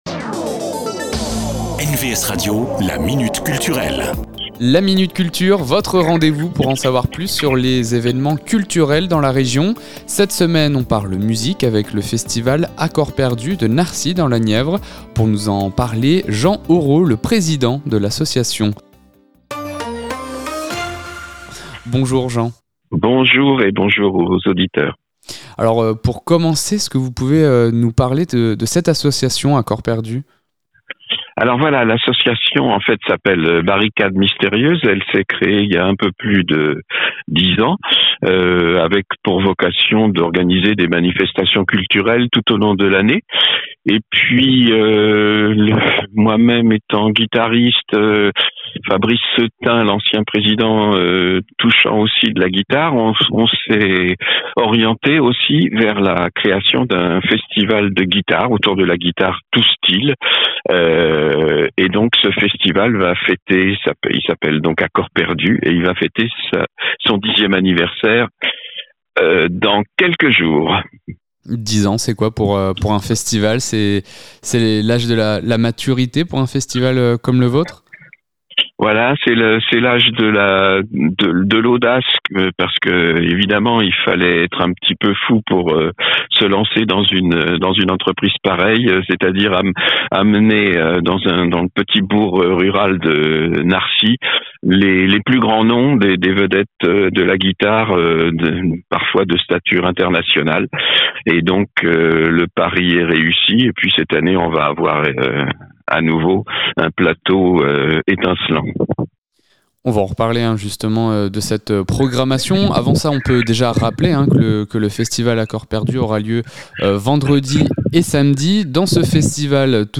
La Minute Culture, rencontre avec les acteurs culturels de la région.